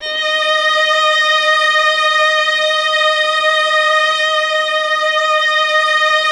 MELLOTRON.12.wav